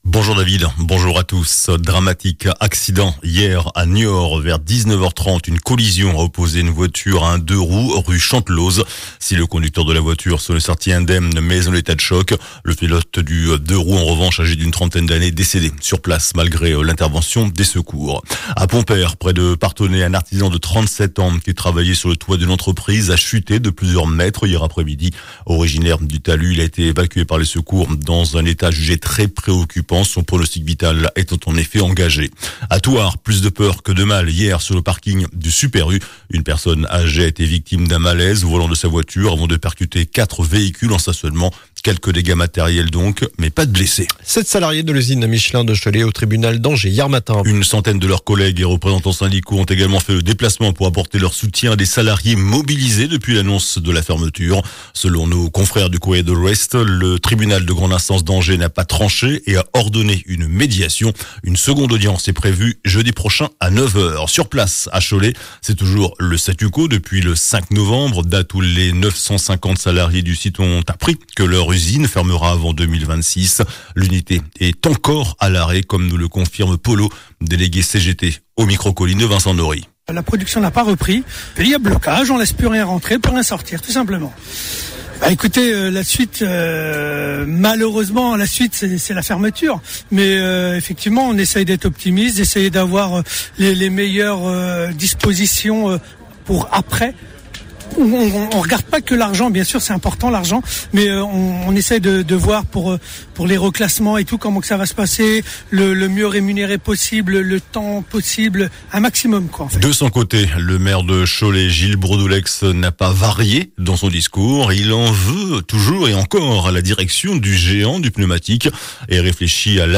JOURNAL DU SAMEDI 23 NOVEMBRE